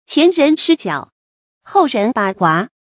qián rén shī jiǎo，hòu rén bǎ huá
前人失脚，后人把滑发音